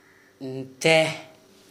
Dialect: Hill